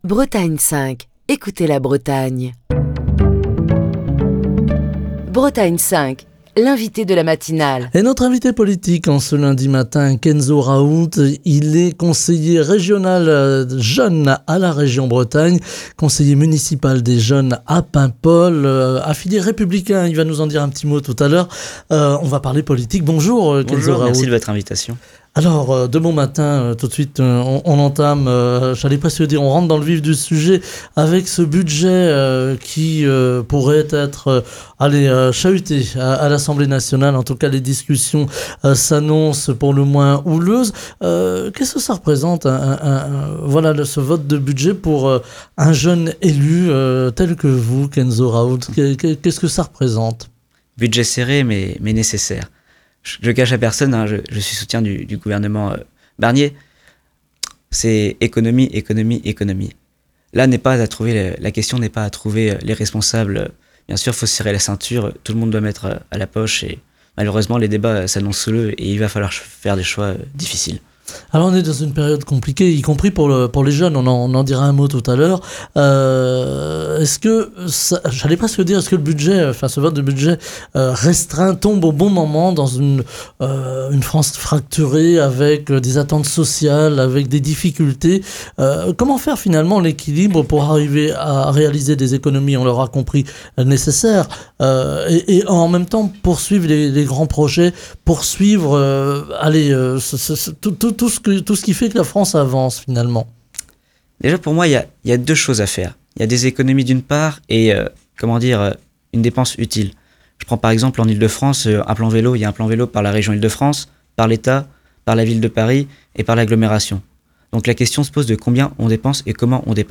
Émission du 21 octobre 2024.